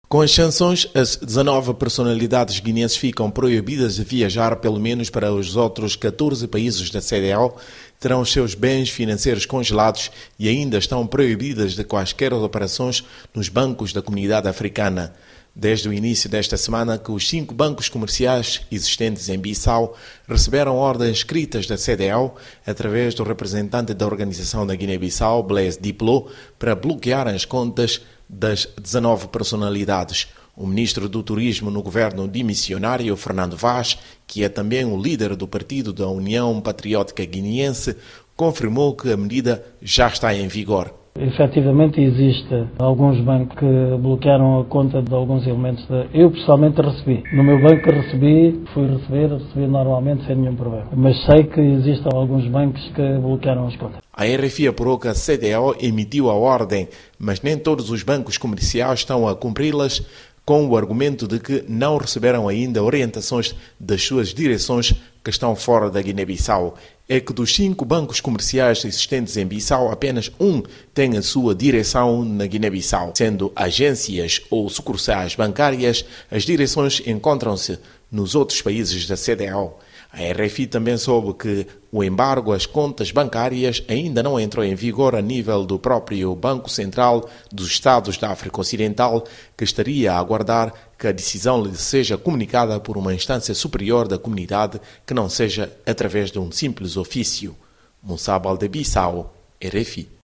correspondente em Bissau
Correspondencia_da_Guine-Bissau.mp3